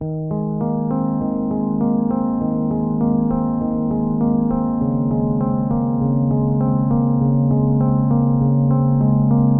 低音吉他 100 BPM
Tag: 100 bpm Chill Out Loops Bass Guitar Loops 1.62 MB wav Key : Unknown